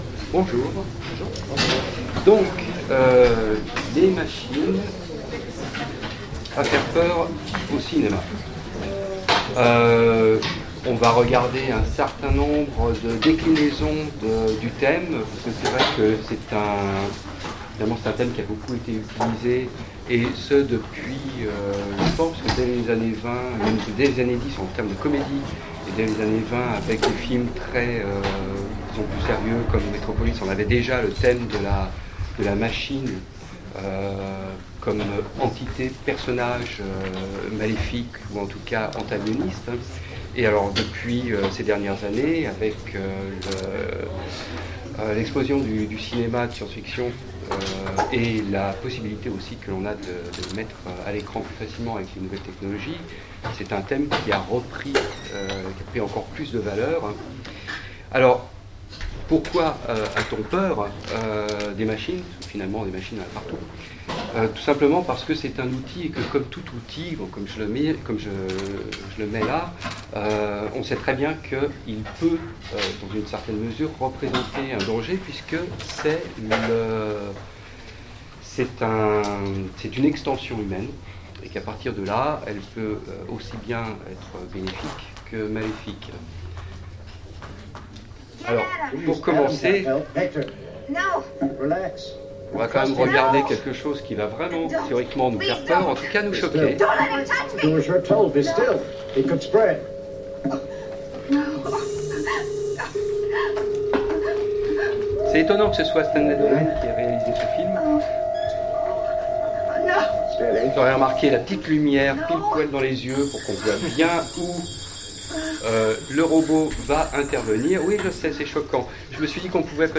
Utopiales 2016 : Cours du soir Machines à faire peur au cinéma
Mots-clés Cinéma Conférence Partager cet article